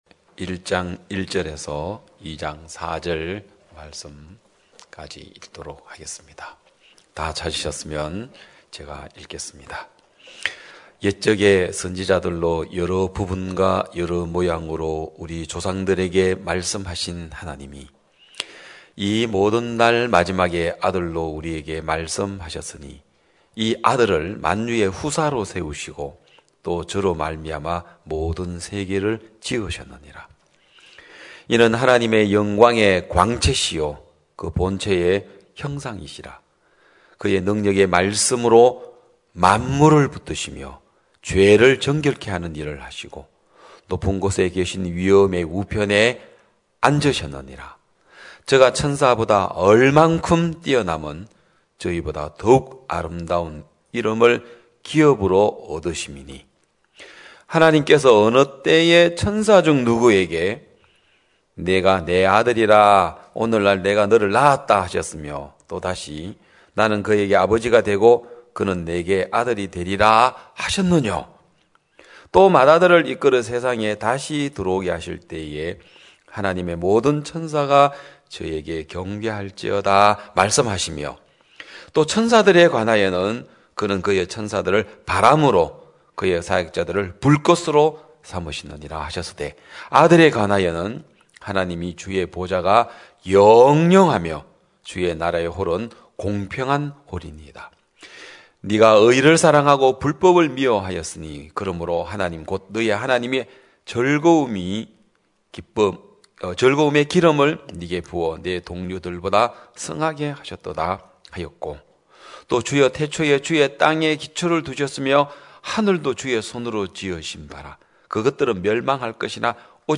2021년 11월 21일 기쁜소식양천교회 주일오전예배